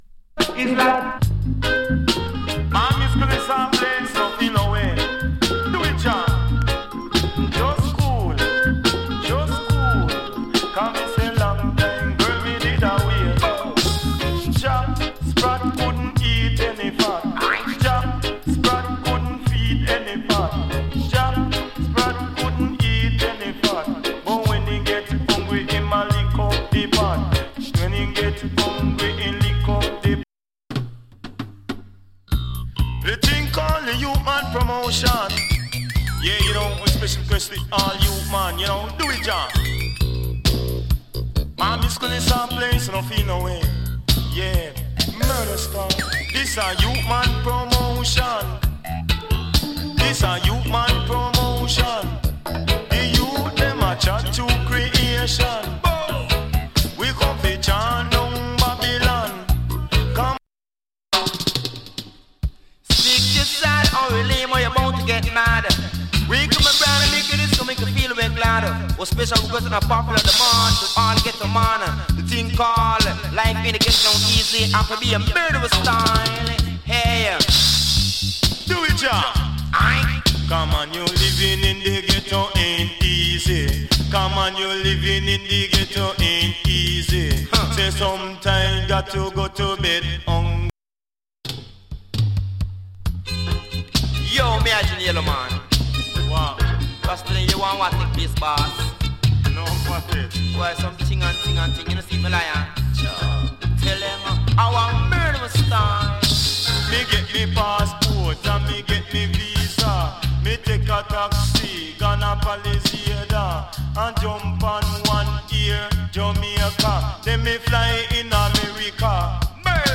DANCE HALL ALBUM